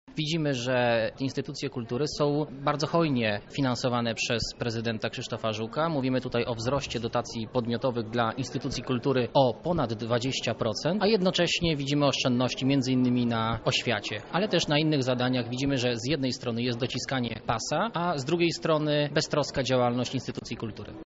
To imponująca kwota, jednak podział środków jest niekorzystny, tak sprawę komentuje Sylwester Tułajew – radny PiS.